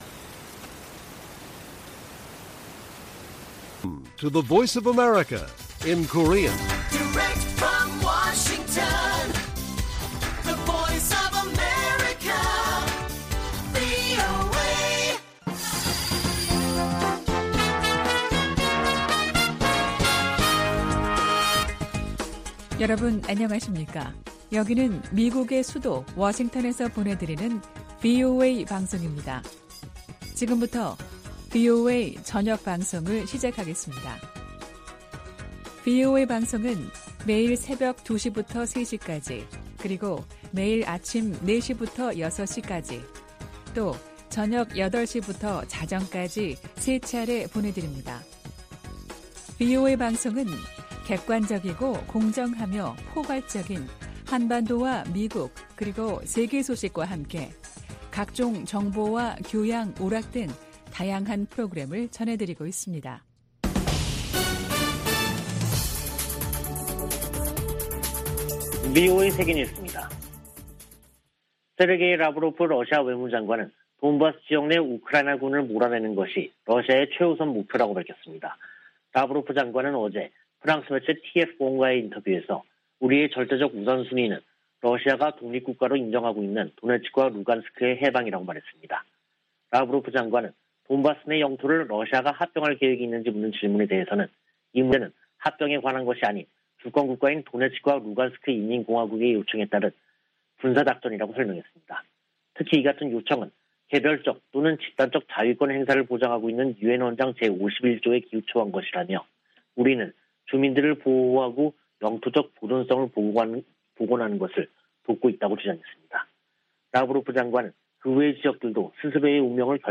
VOA 한국어 간판 뉴스 프로그램 '뉴스 투데이', 2022년 5월 30일 1부 방송입니다. 미 재무부가 북한의 최근 탄도미사일 발사에 대응해 북한 국적자와 러시아 기관들을 추가 제재했습니다. 미한일 외교장관들은 유엔 안보리가 새 대북 결의안 채택에 실패한 데 유감을 나타내고 3국 협력은 물론 국제사회와의 조율을 강화하겠다고 밝혔습니다. 미 국방부는 북한이 계속 불안정을 야기한다면 군사적 관점에서 적절한 대응을 위한 방안을 찾을 것이라고 밝혔습니다.